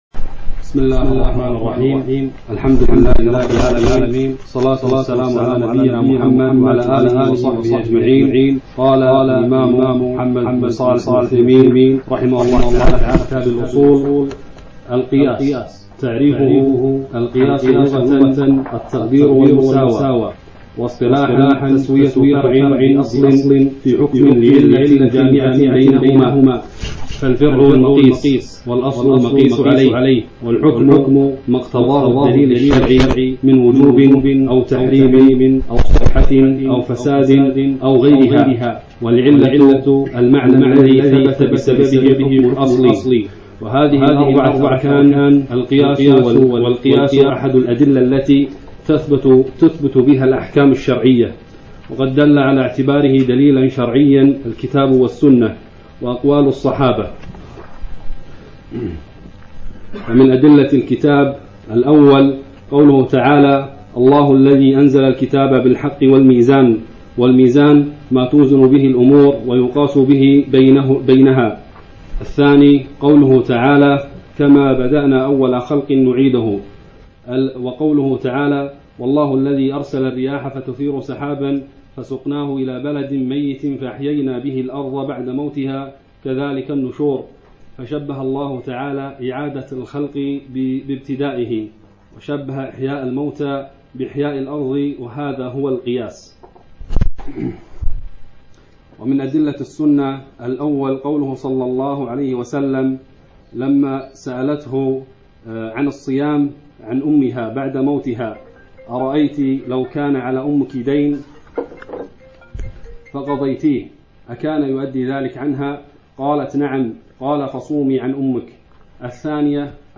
الدرس الأول : القياس